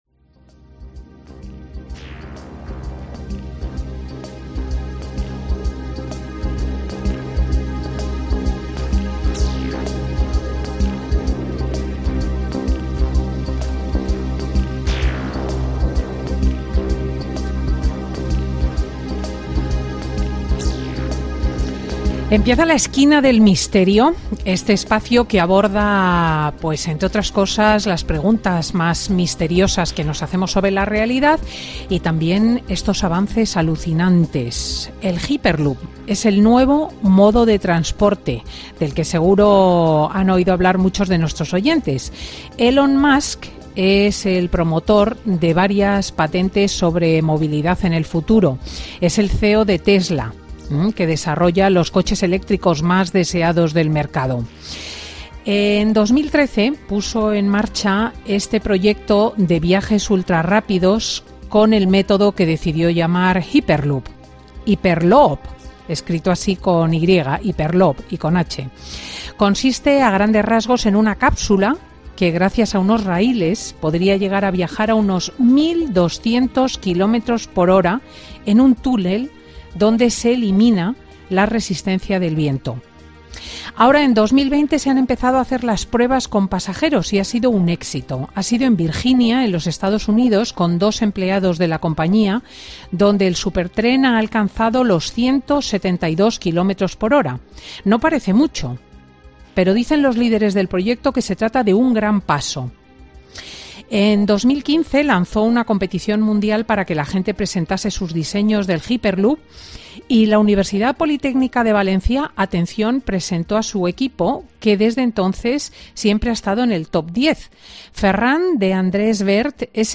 Entrevista a la teniente coronel médico del Cuerpo Militar de Sanidad